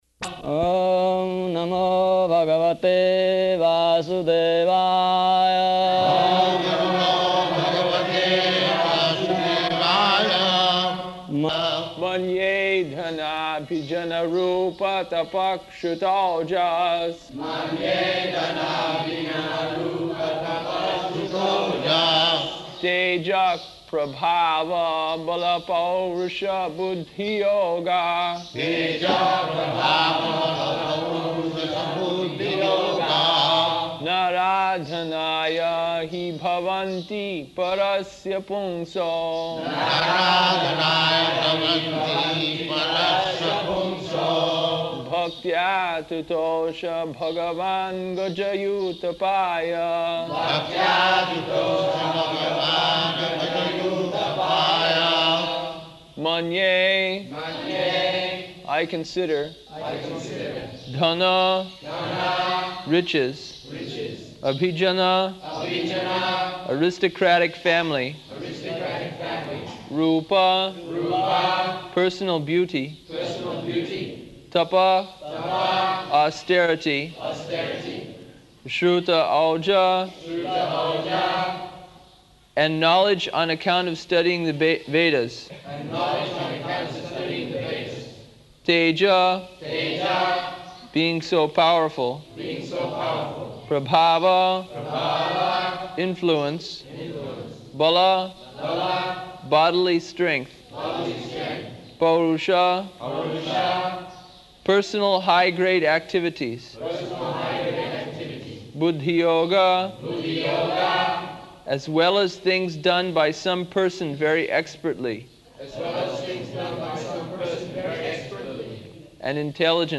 -- Type: Srimad-Bhagavatam Dated: February 16th 1976 Location: Māyāpur Audio file
[Prabhupāda and devotees repeat] [chants verse, with Prabhupāda and devotees repeating]